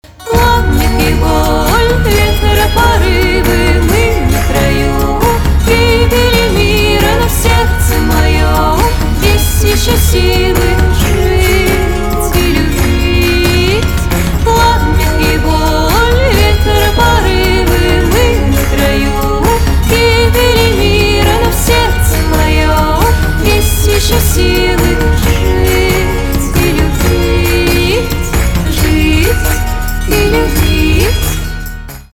фолк
барабаны , гитара , чувственные